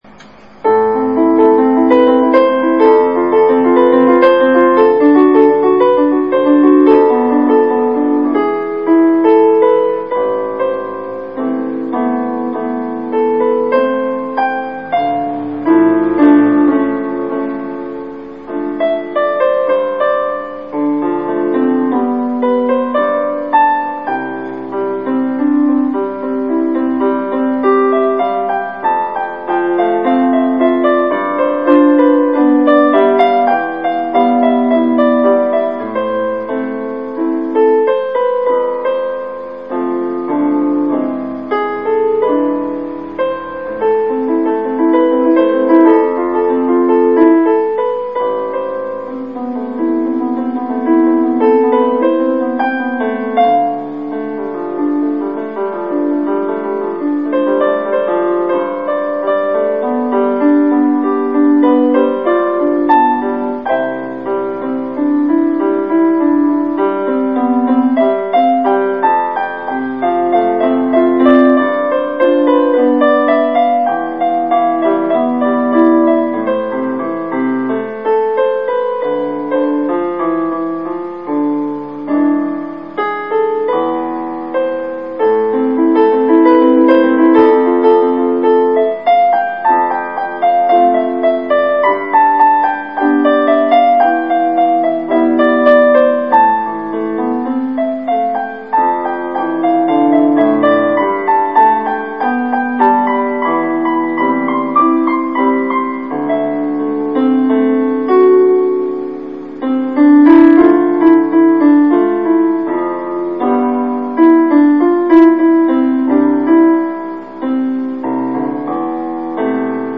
Partitura para piano / Piano score (pdf)
Escuchar partitura / Listen score (MP3)(Human)